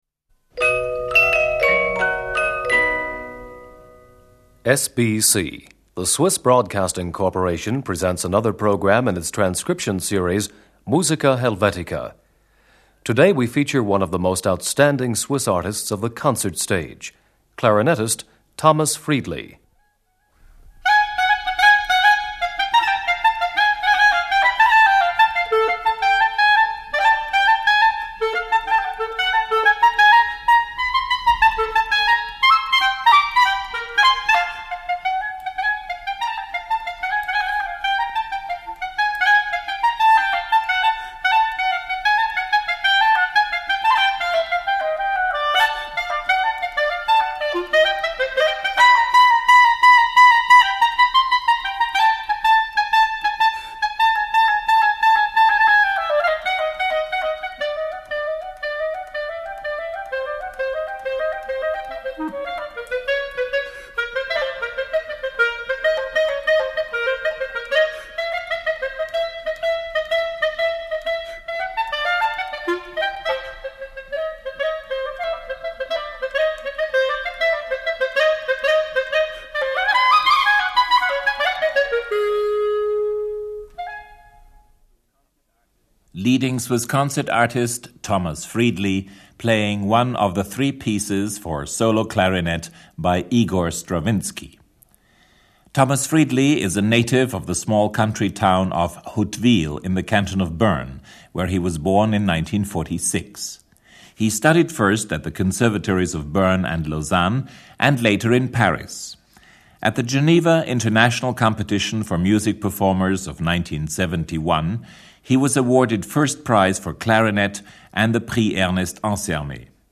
clarinet. Orchestre de la Suisse Romande.
Lausanne Chamber Orchestra.